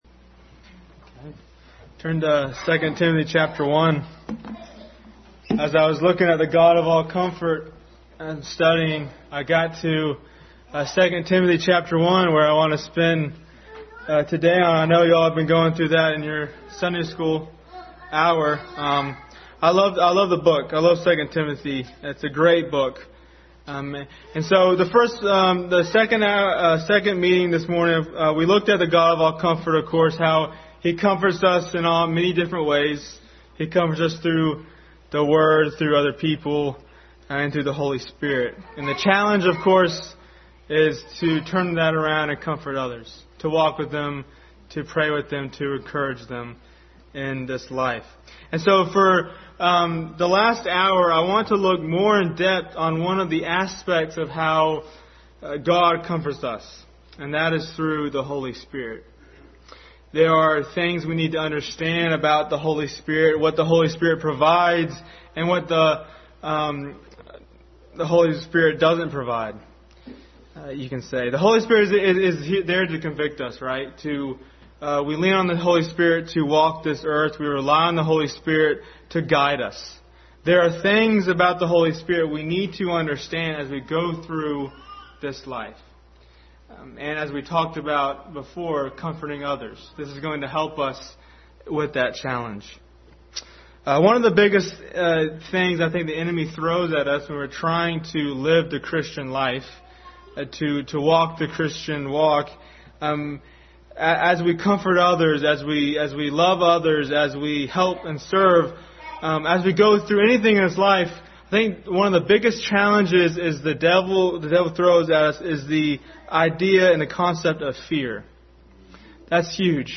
Passage: 2 Corinthians 1:3-7 Service Type: Family Bible Hour